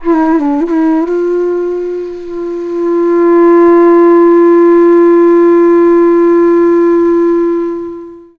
FLUTE-A01 -L.wav